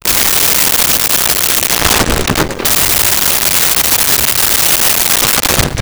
Explosion Debris Sweetener 02
Explosion Debris Sweetener 02.wav